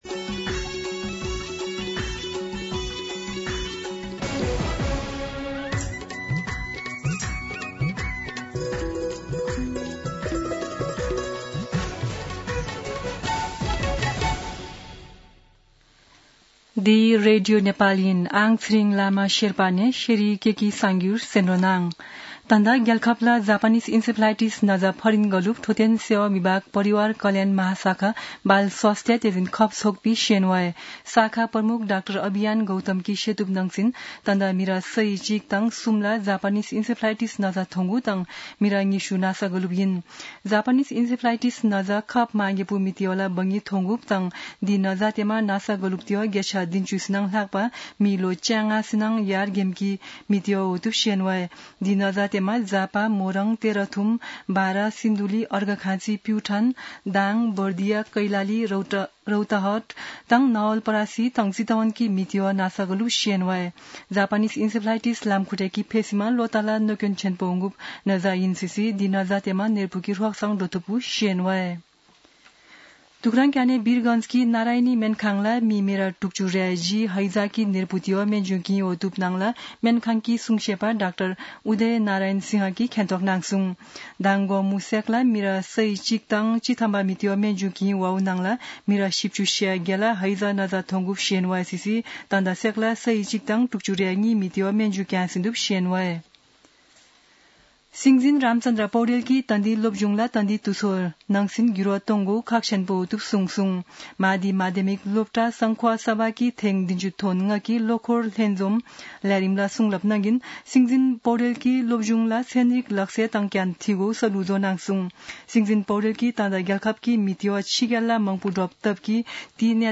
शेर्पा भाषाको समाचार : १२ भदौ , २०८२
Sherpa-News-05-12.mp3